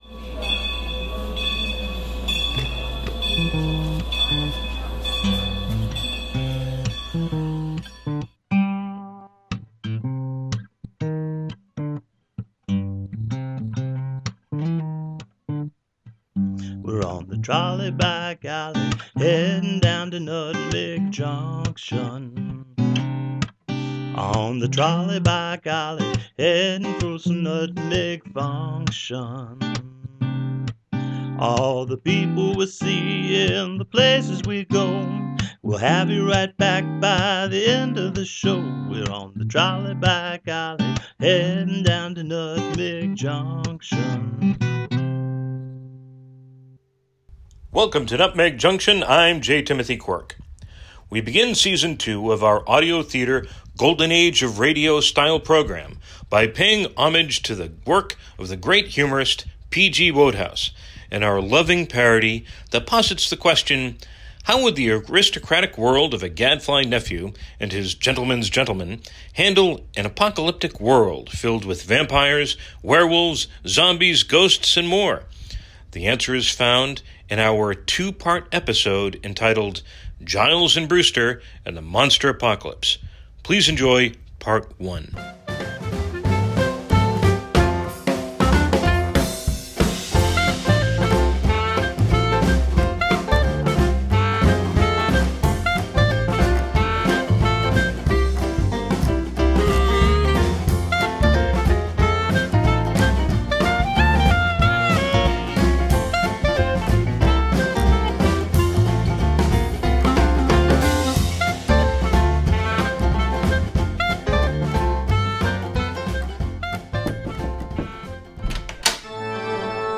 We begin Season 2 of our audio theater golden-age-of-radio style program by paying homage to the work of the great humorist PG Wodehouse in our loving parody that posits the question, how would the aristocratic world of a gadfly nephew and his gentleman’s gentleman handle an apocalyptic world filled with vampires, werewolves, zombies and more.
The program was recorded at WAPJ, Torrington Community Radio